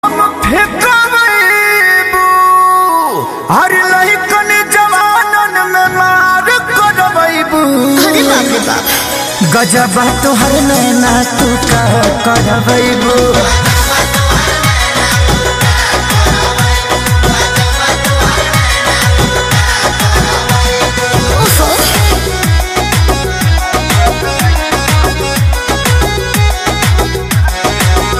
Romantic Bhojpuri hit ft.
Mesmerizing tune for calls, alerts.